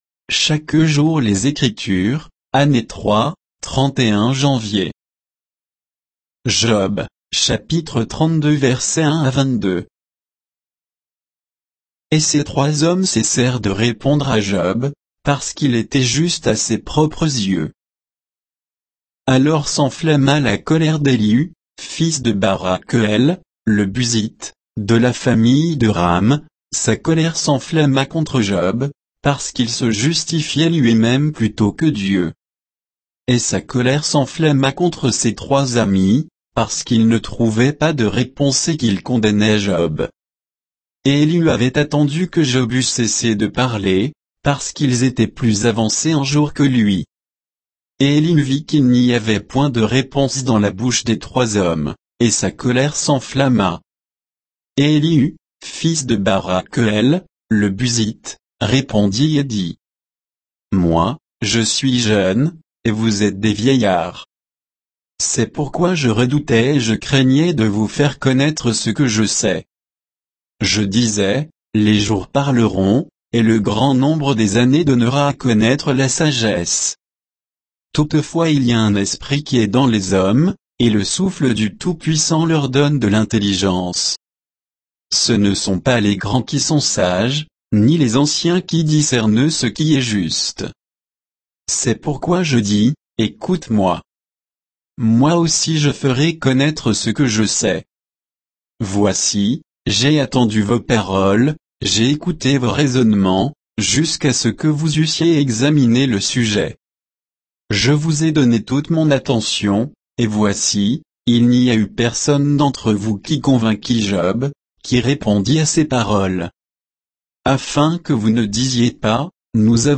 Méditation quoditienne de Chaque jour les Écritures sur Job 32